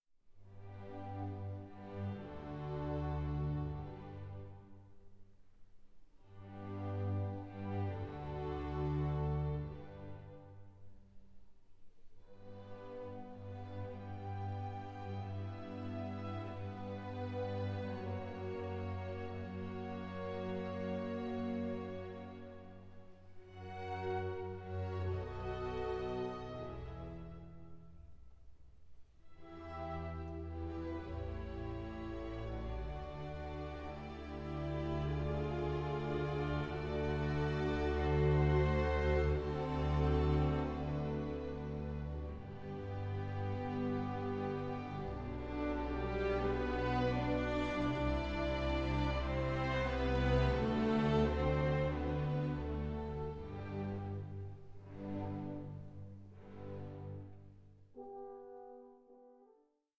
Larghetto 9:27